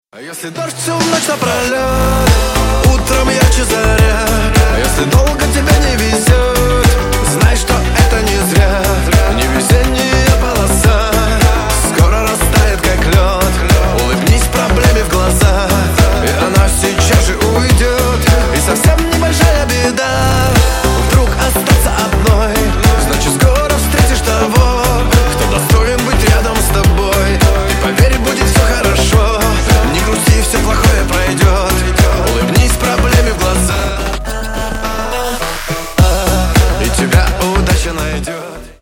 Рингтоны Ремиксы » # Танцевальные Рингтоны